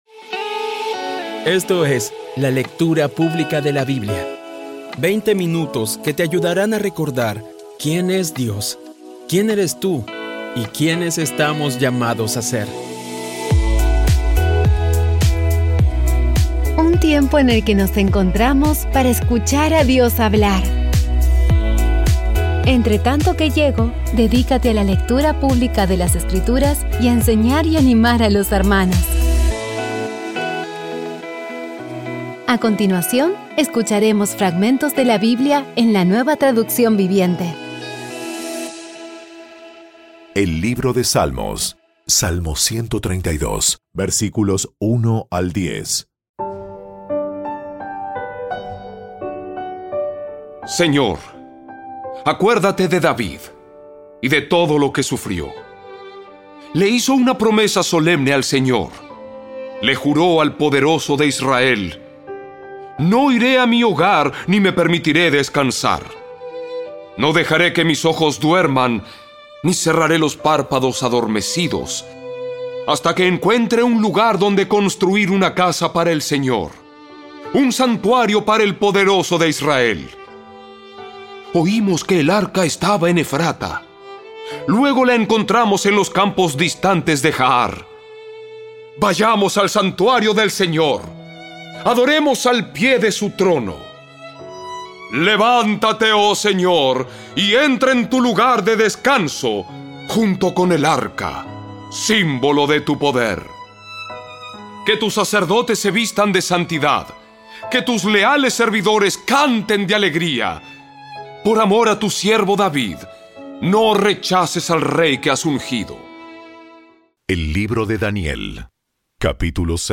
Audio Biblia Dramatizada Episodio 330
Poco a poco y con las maravillosas voces actuadas de los protagonistas vas degustando las palabras de esa guía que Dios nos dio.